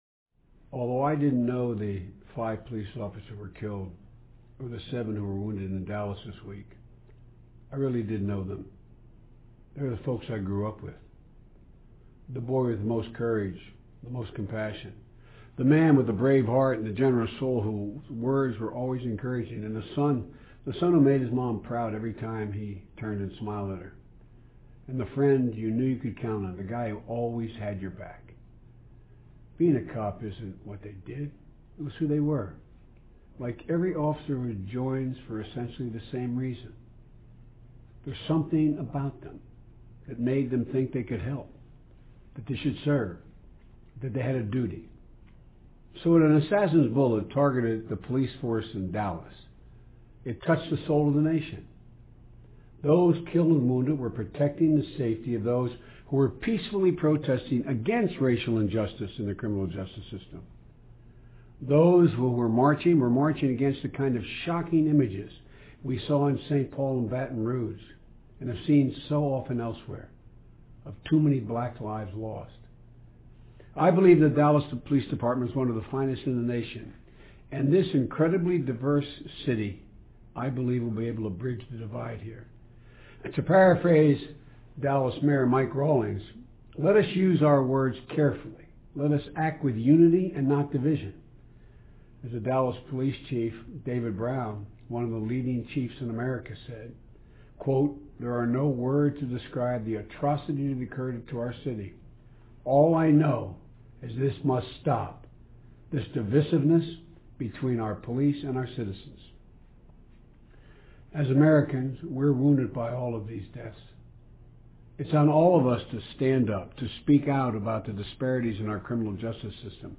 奥巴马每周电视讲话：团结一致遏制暴力 听力文件下载—在线英语听力室